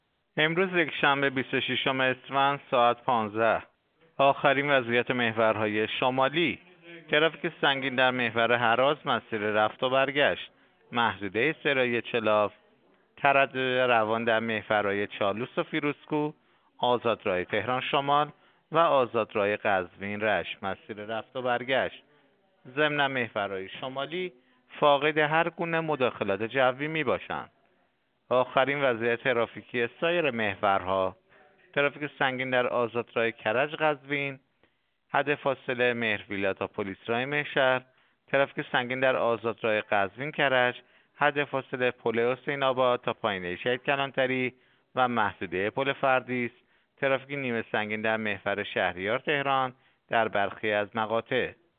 گزارش رادیو اینترنتی از آخرین وضعیت ترافیکی جاده‌ها ساعت ۱۵ بیست و ششم اسفند؛